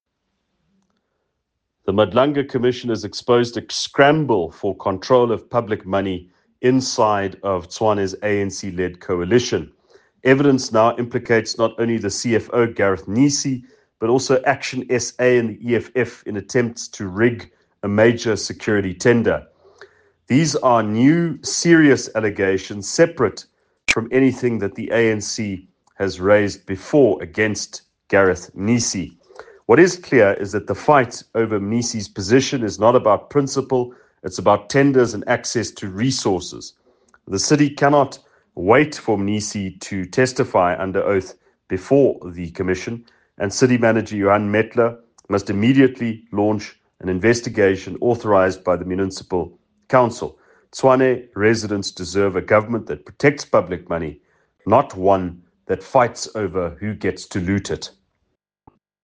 Afrikaans soundbites by Ald Cilliers Brink